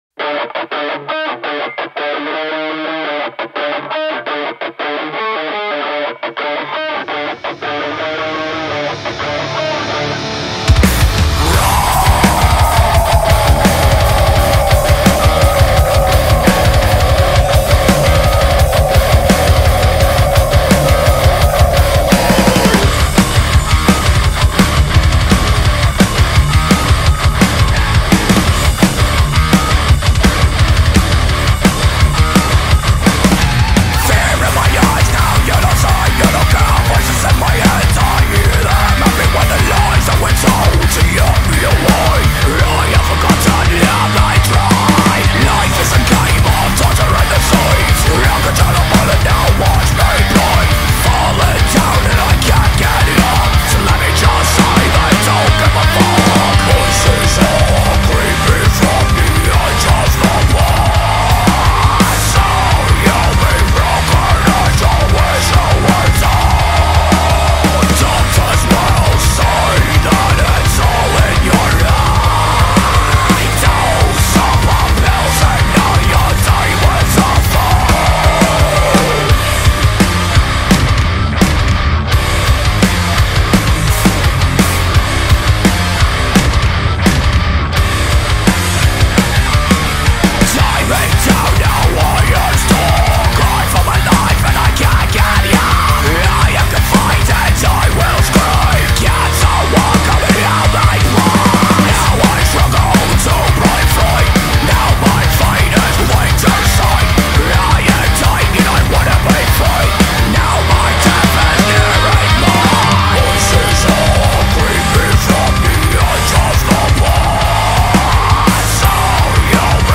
the debut single from UK metalcore band